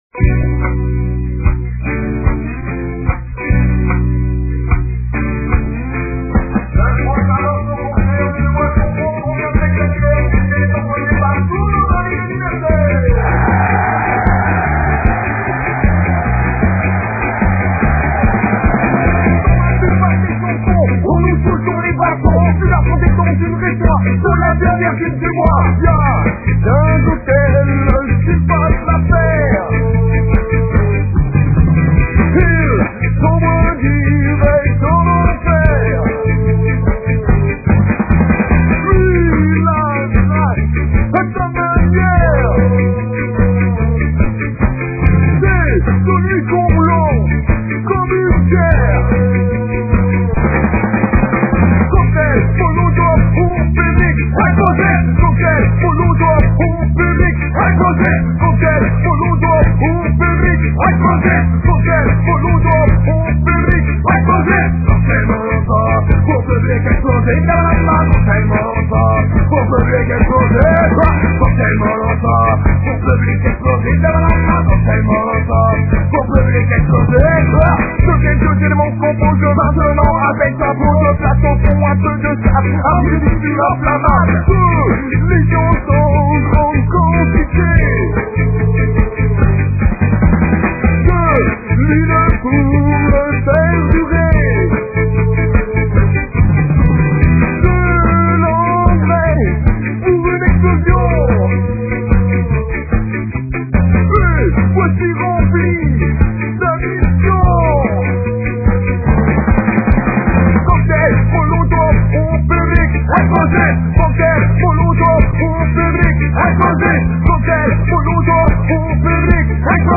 Les fichiers sont en mp3 et de qualité assez mauvaise .